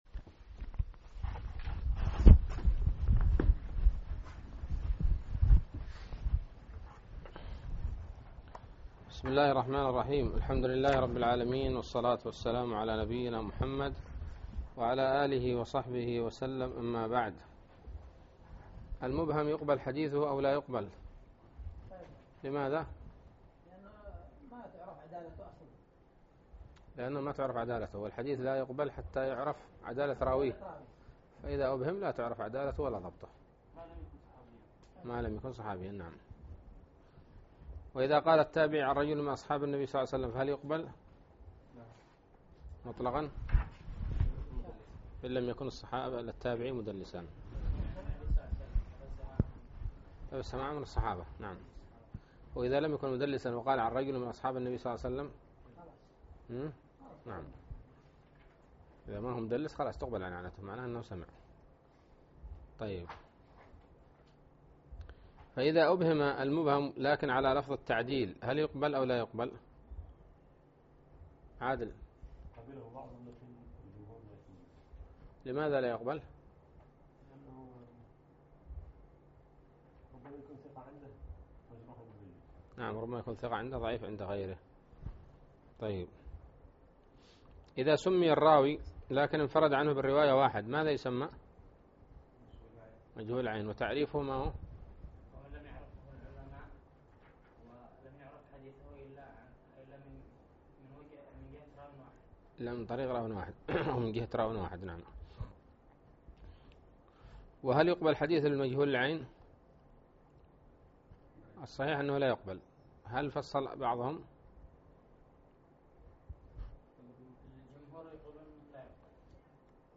الدرس التاسع والعشرون من شرح نزهة النظر